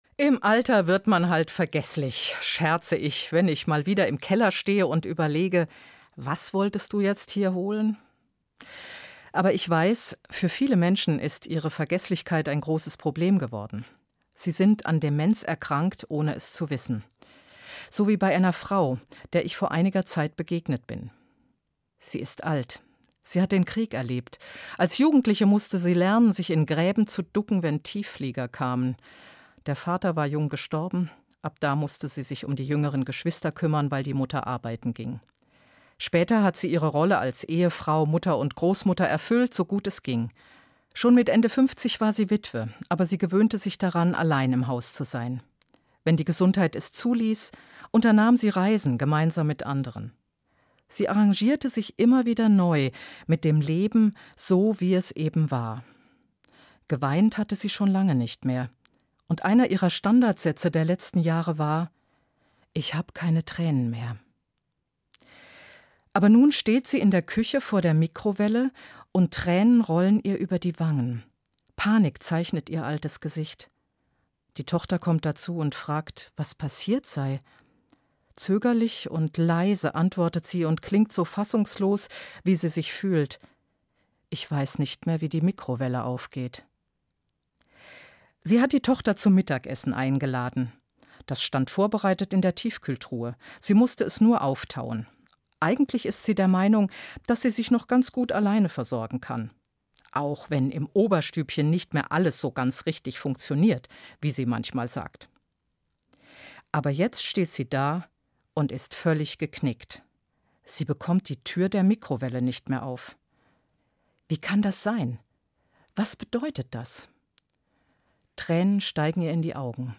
Eine Sendung